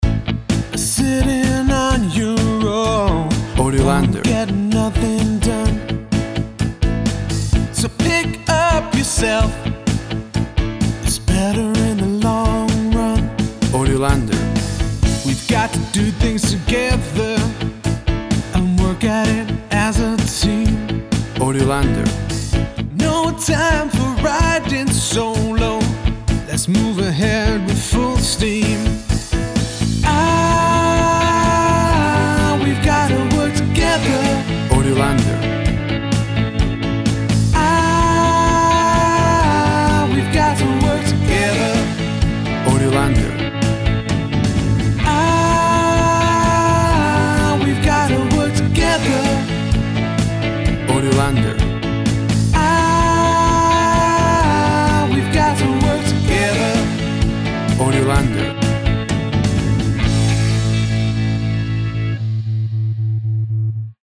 Tempo (BPM) 125